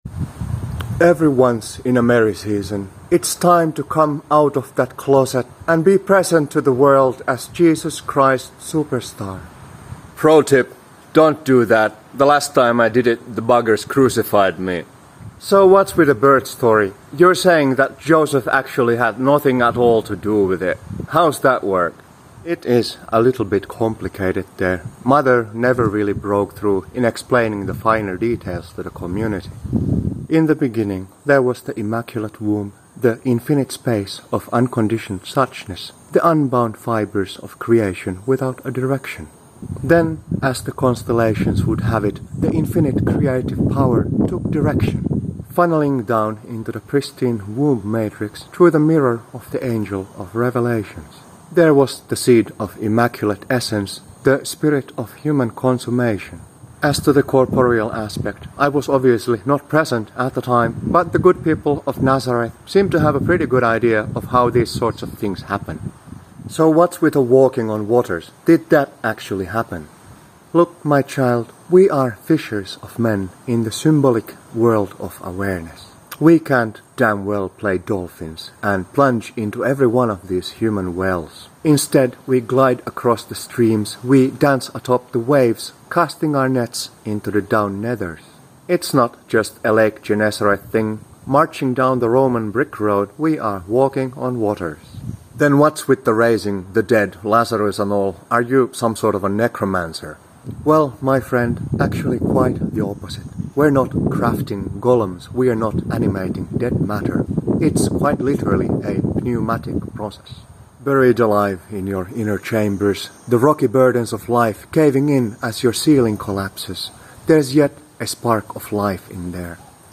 Just for a little interview.